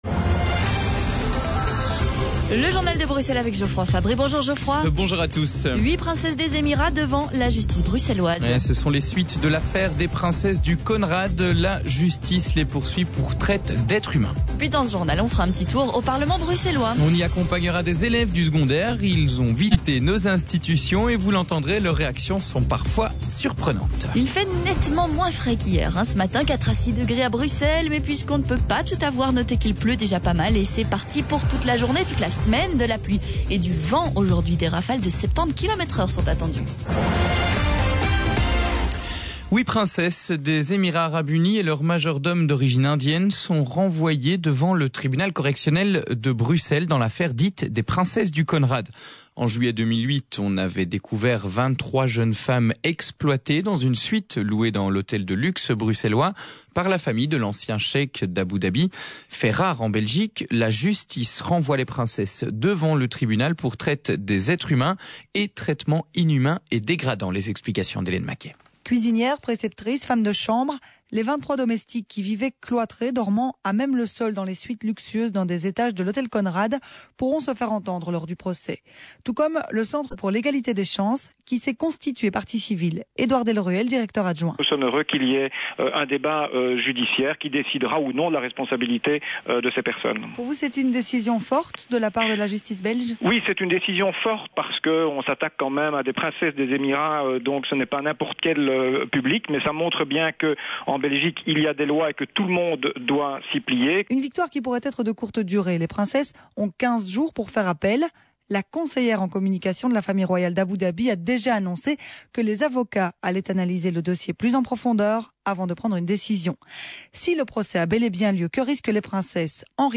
Reportage Vivacité P-Day